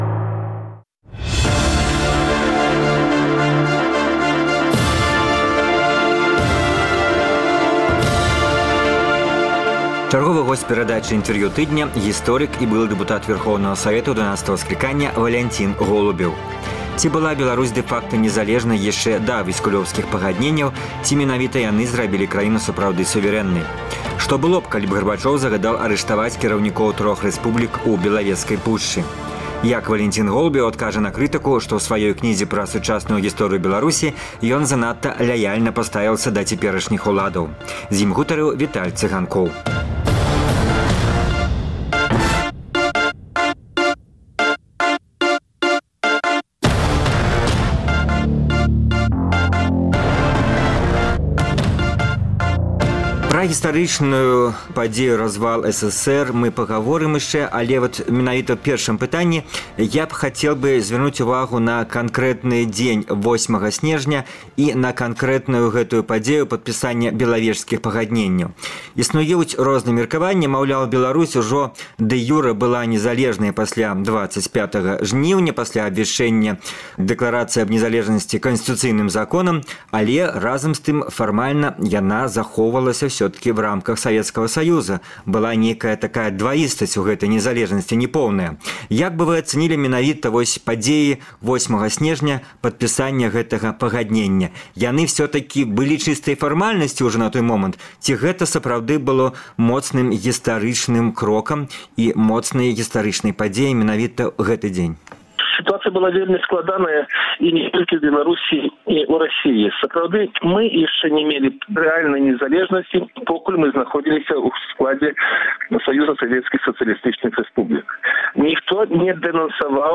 Чарговы госьць перадачы “Інтэрвію тыдня” -- гісторык і былы дэпутат Вярхоўнага Савету 12-га скліканьня Валянцін Голубеў. Ці была Беларусь дэ-факта незалежнай яшчэ да Віскулёўскіх пагадненьняў ці менавіта яны зрабілі краіну сапраўды сувэрэннай? Што было б, калі б Гарбачоў загадаў арыштаваць кіраўнікоў трох рэспублік у Белавескай пушчы?